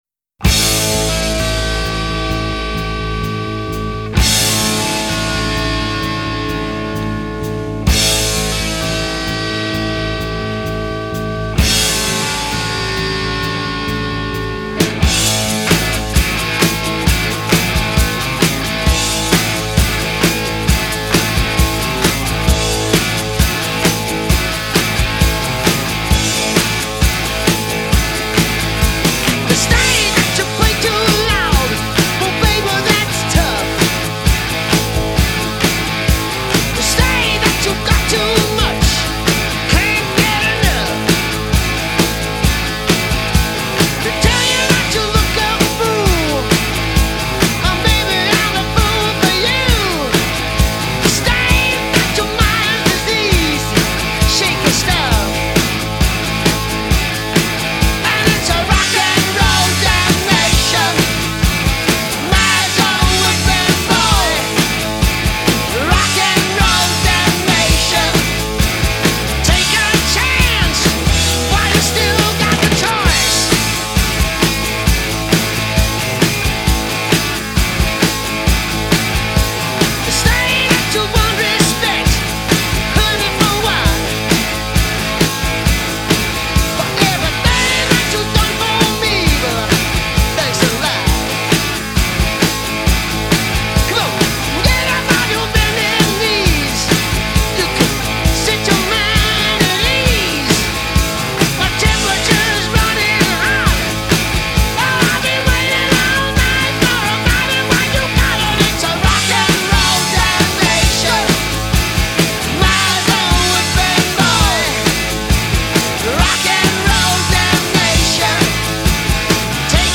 Genre: Rock
Style: Hard Rock, Arena Rock